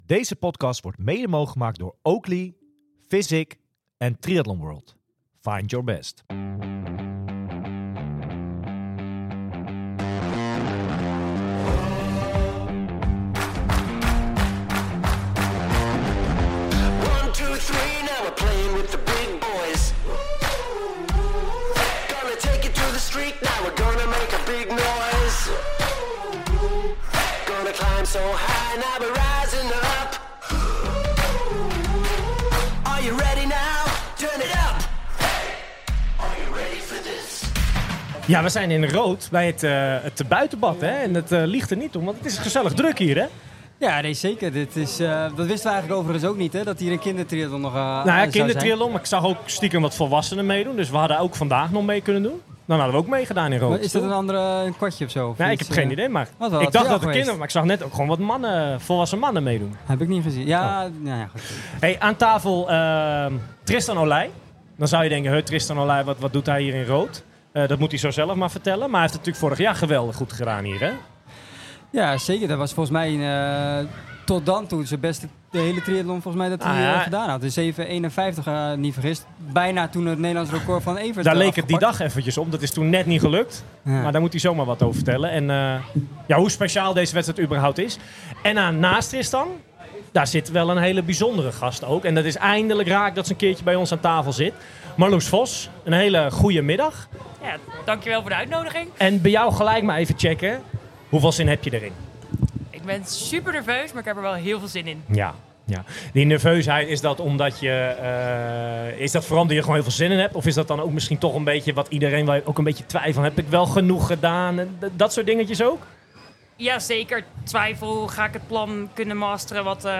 In deze uitzending hebben we het over de race van morgen met verschillende gasten en voor het eerst live met publiek!